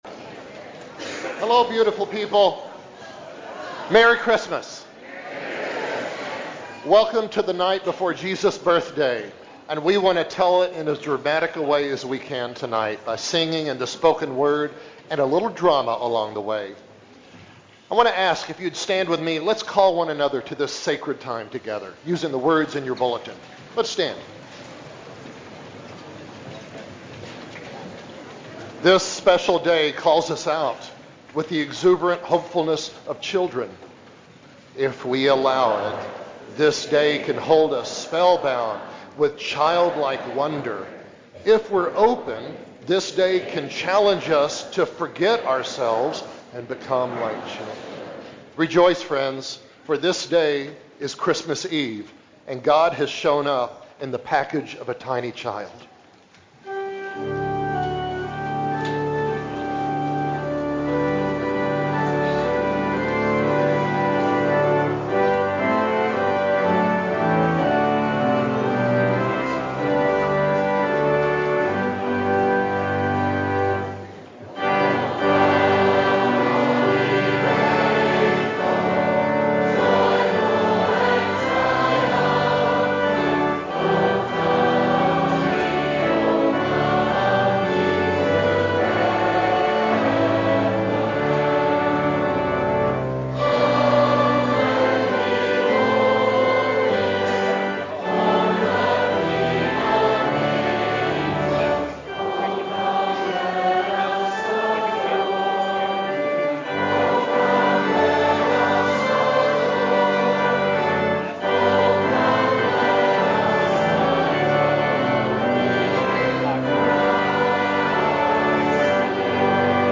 Christmas Eve – Children’s Nativity